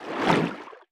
File:Sfx creature seamonkey swim fast 03.ogg - Subnautica Wiki